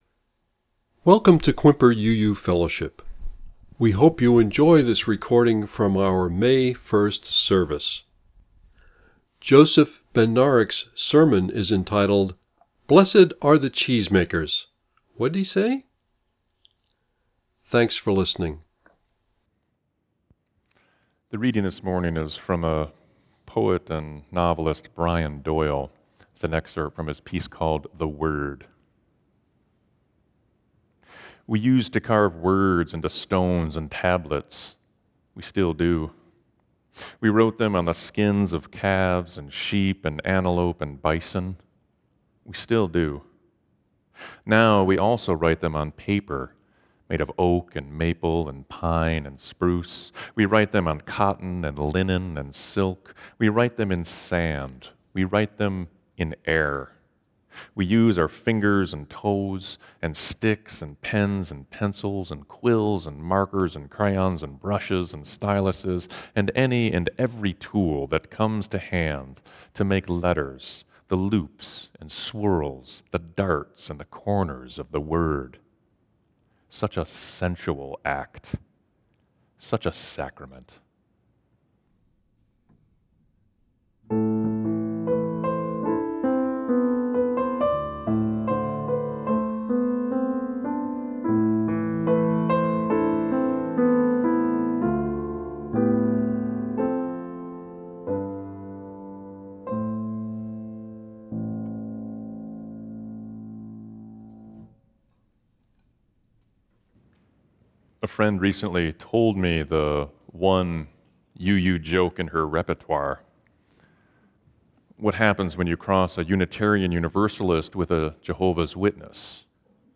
Click here to listen to the reading and sermon.